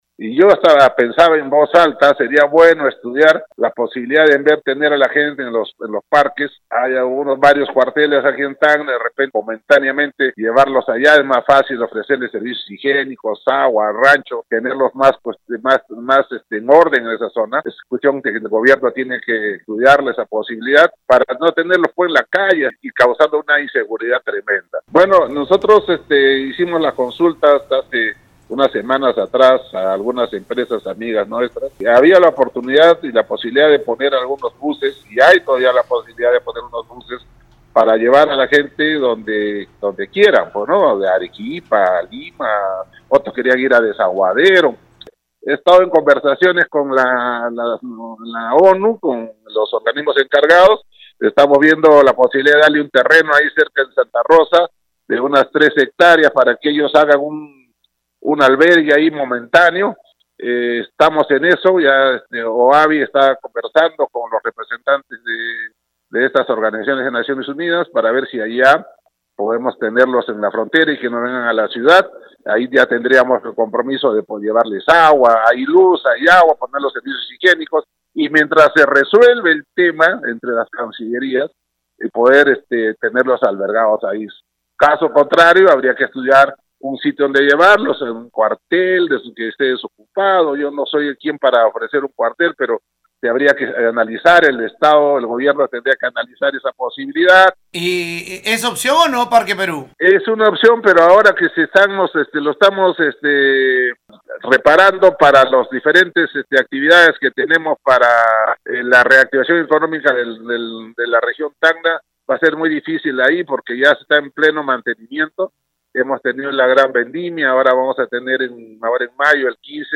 “Yo hasta pensaba en voz alta, sería bueno estudiar la posibilidad en vez de tener a las personas en los parques, hay varios cuarteles aquí en Tacna, de repente momentáneamente llevarlos allá“, expresó vía Radio Uno.
LUIS-TORRES-ROBLEDO.mp3